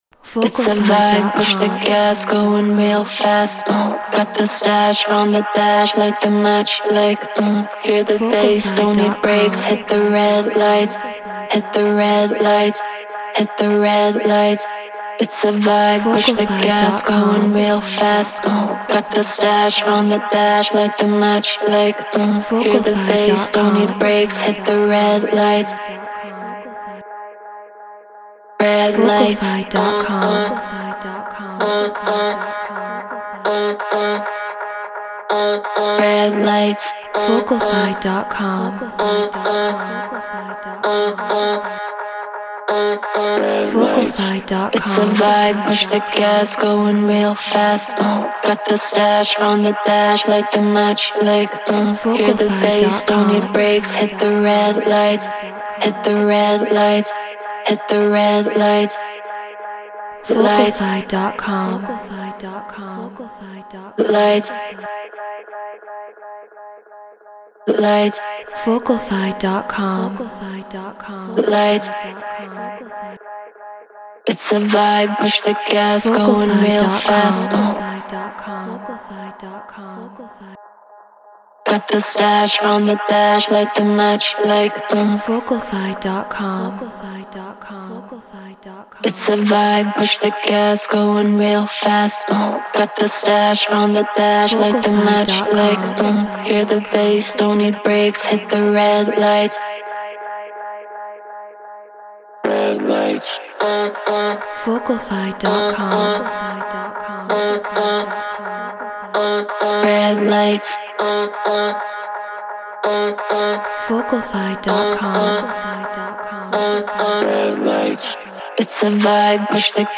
Tech House 130 BPM G#min
Shure KSM 44 Apollo Twin X Pro Tools Treated Room